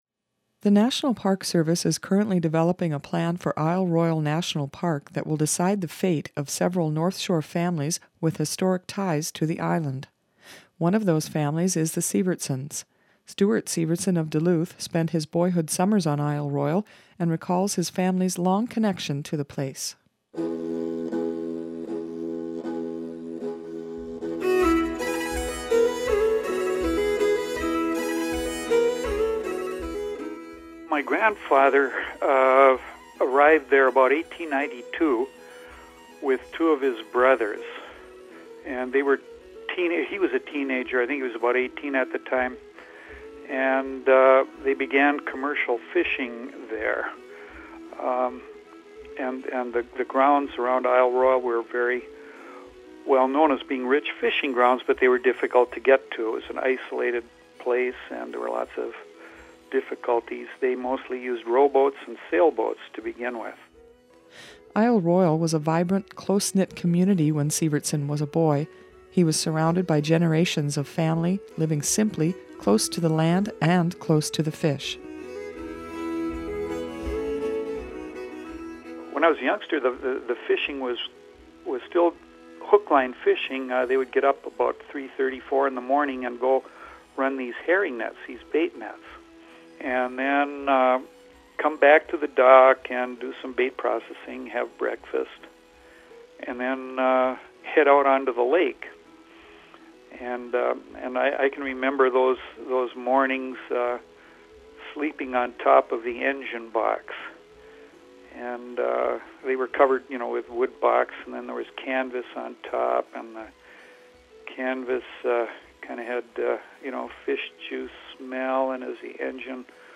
In this feature from the WTIP news department